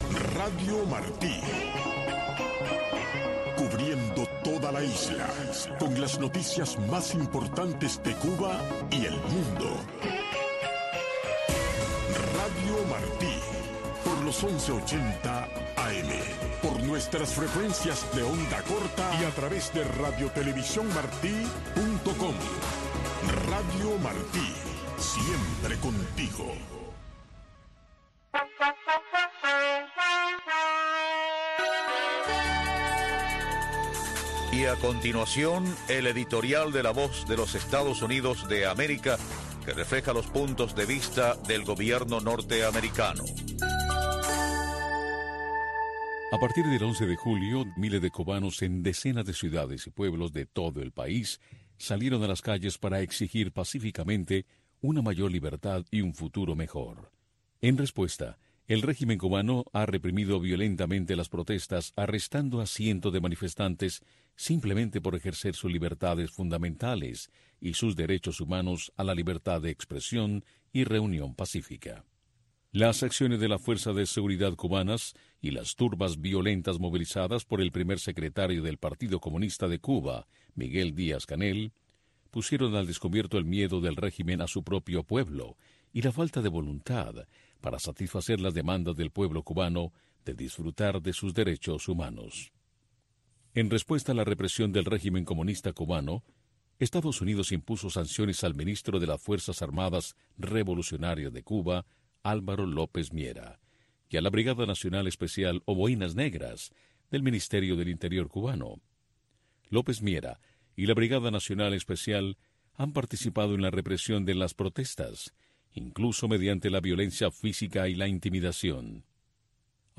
Radio Martí les ofrece una revista de entrevistas, información de la actualidad mundial vista desde el punto de vista, Entre Dos Rios.